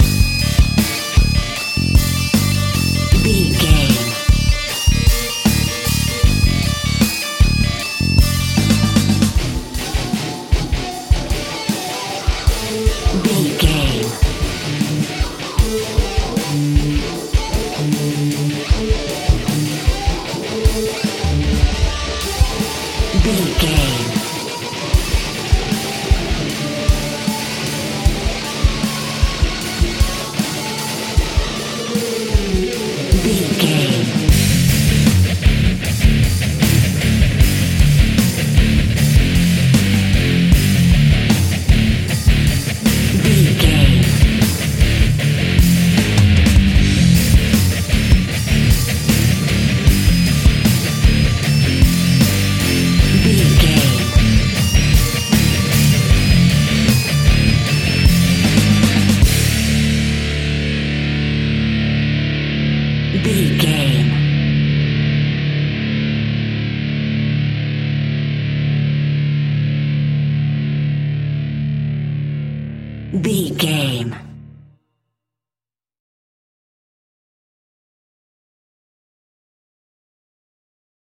Epic / Action
Fast paced
Aeolian/Minor
heavy metal
Rock Bass
Rock Drums
heavy drums
distorted guitars
hammond organ